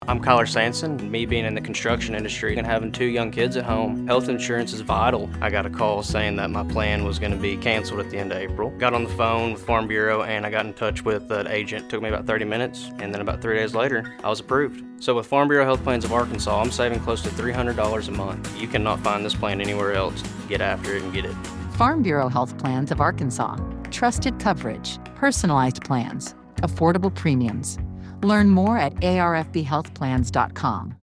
• Allow new members to tell their stories in their own words versus following a script.